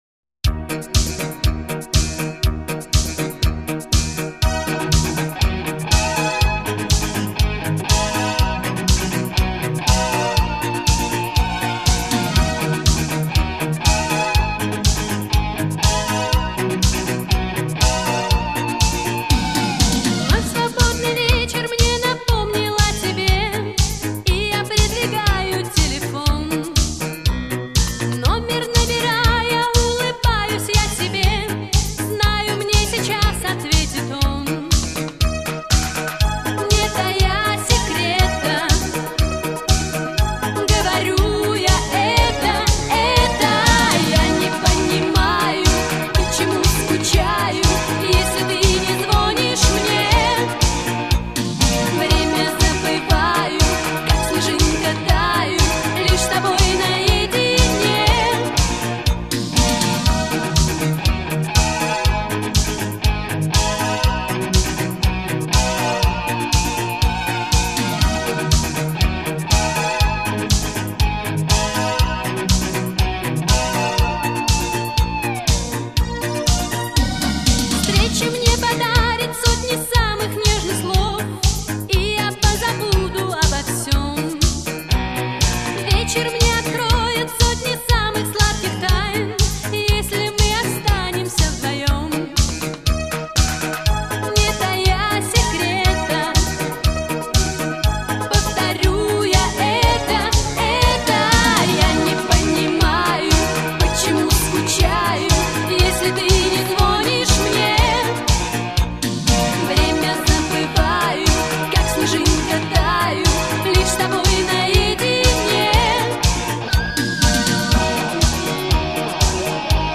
в клубной обработке
музыка 80х в современной обработке